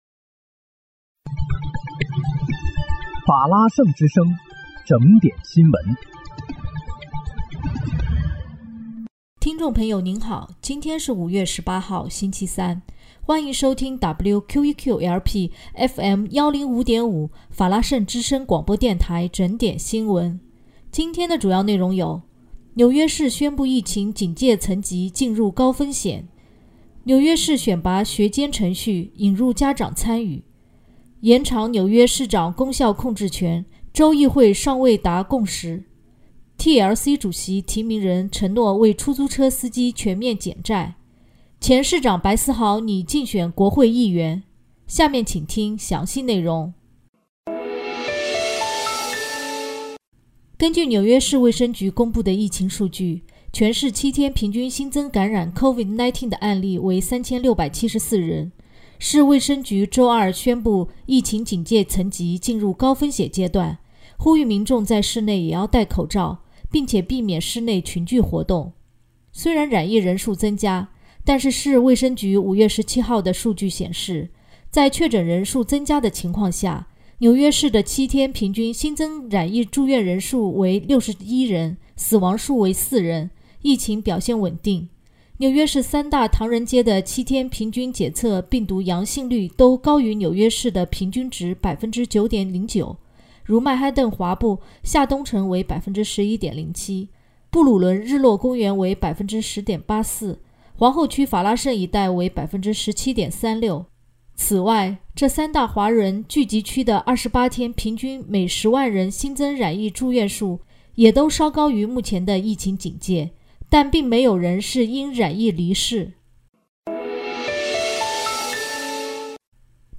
5月18日（星期三）纽约整点新闻